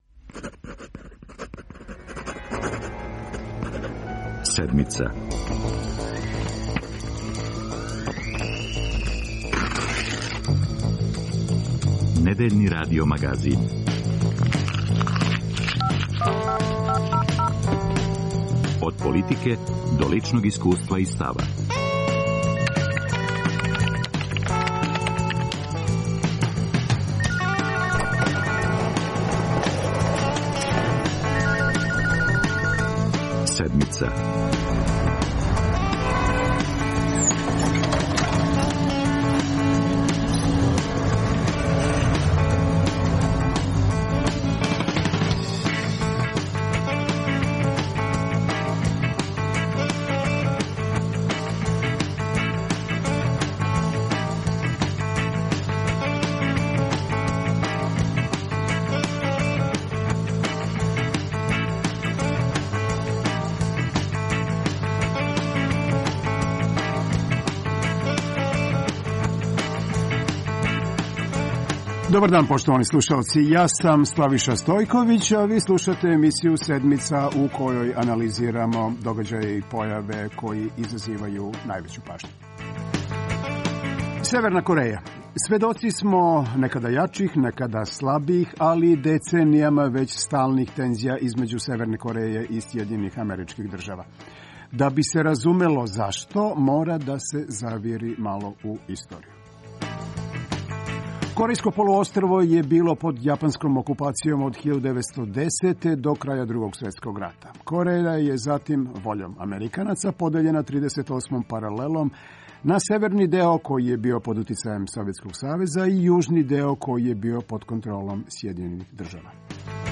За Седмицу говоре: спољнополитички коментатори